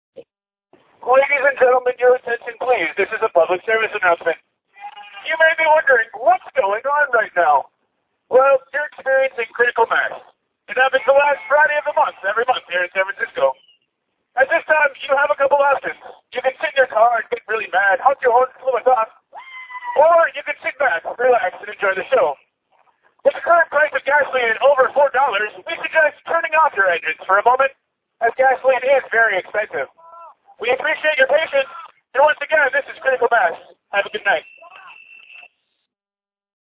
Sorry, I'm speaking through a bullhorn at maximum volume.
CAUTION: This is loud.